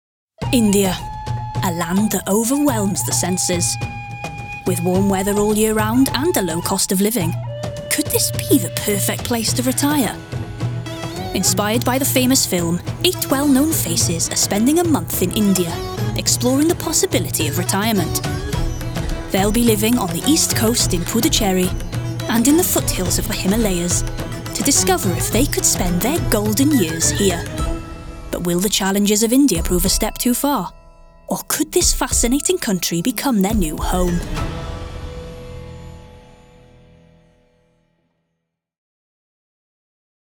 Narration Showreel
Non-binary
Friendly
Playful
Upbeat
Voice Next Door